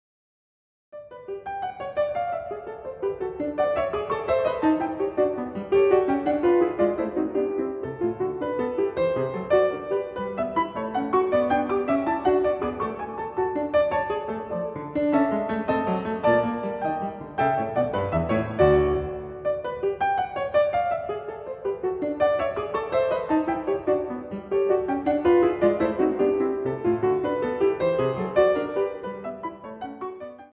short pieces for the piano